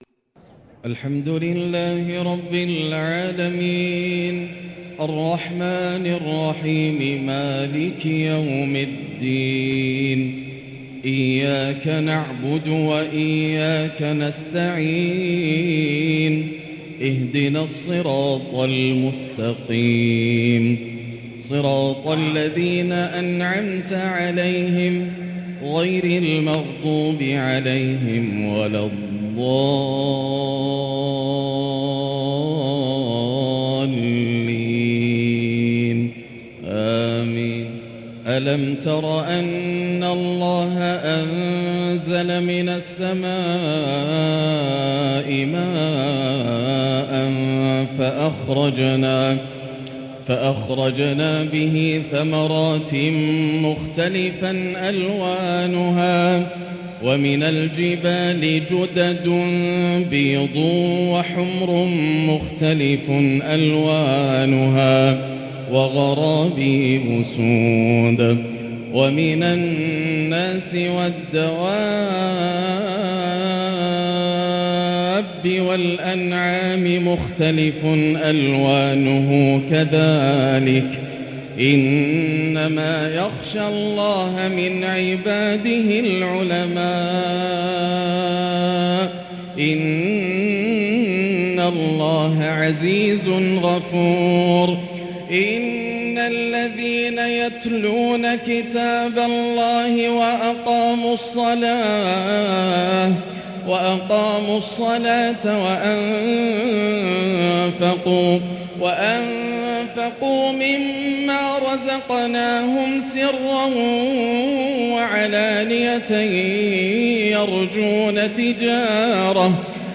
تنوع مذهل و تغنّي يأسر الألباب باللامي للغريد الآسر د.ياسر الدوسري من مهبط الوحي > مقتطفات من روائع التلاوات > مزامير الفرقان > المزيد - تلاوات الحرمين